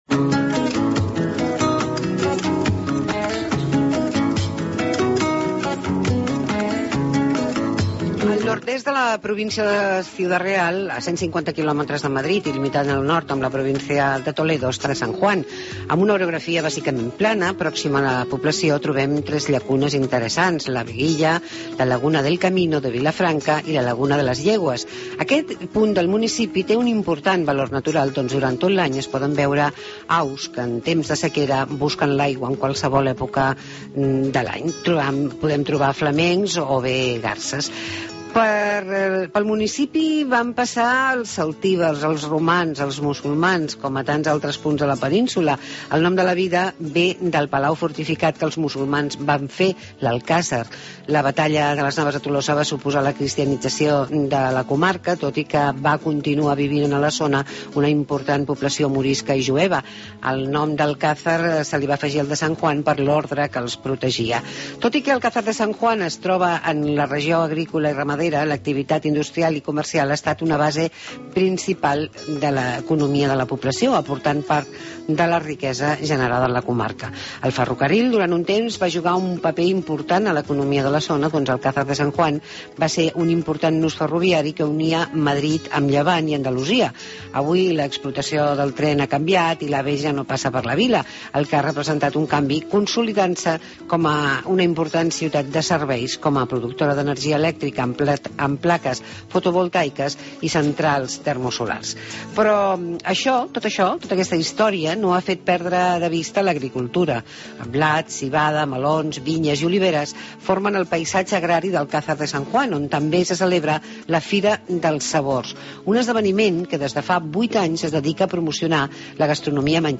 El alcalde de Alcázar de San Juan, Diego Ortega, nos habla de la Feria de los Sabores